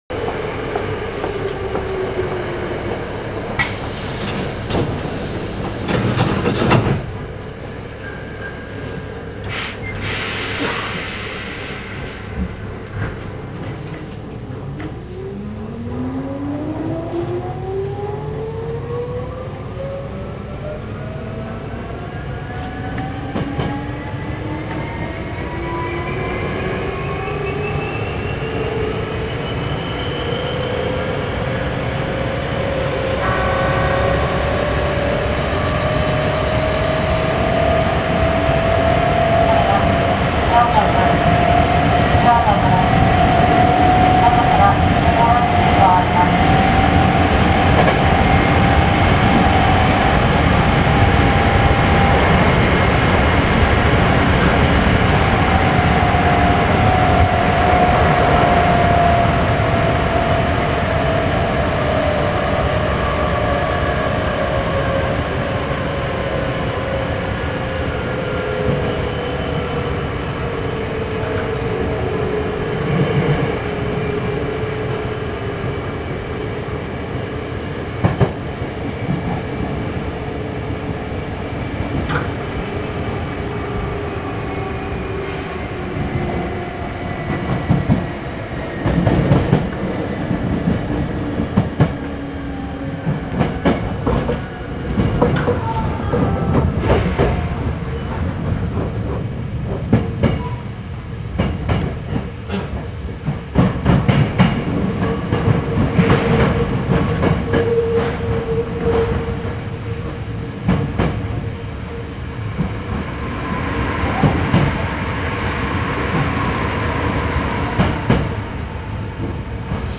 ・7300系走行音
【京都線】摂津市→正雀（2分21秒：771KB）…収録は7304Fにて
ごく普通の界磁チョッパ制御車の音。決して静かではありませんが3300系などと比べれば大分音量は控えめになっている気がします。